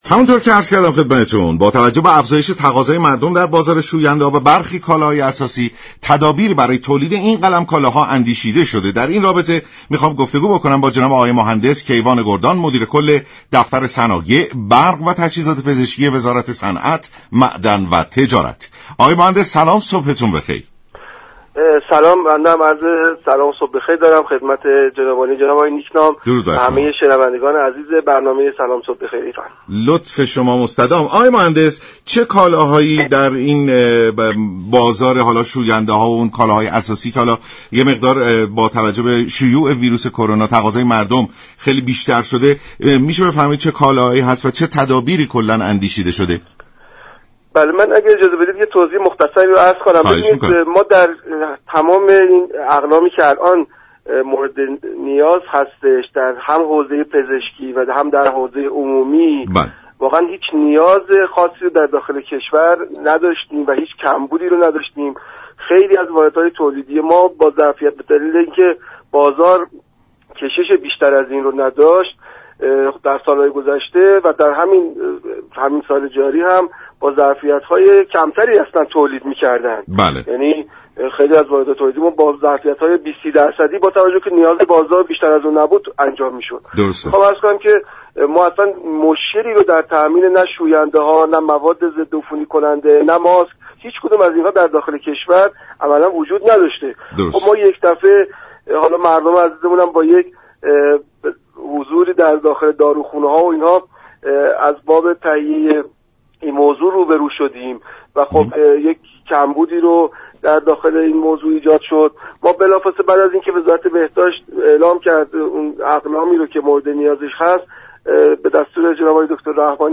برنامه «سلام صبح بخیر» شنبه تا چهارشنبه هر هفته ساعت 6:35 از رادیو ایران پخش می شود.